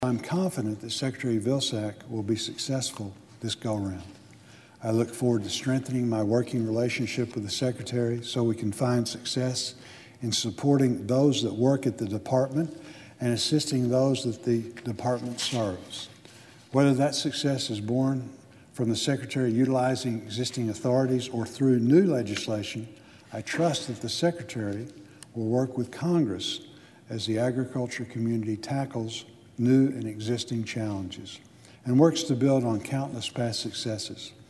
Senate Ag Committee Ranking Member John Bozeman (R-Arkansas) also rose and showed his support. He spoke to the bipartisan nature of the committee?s work to get the nomination through.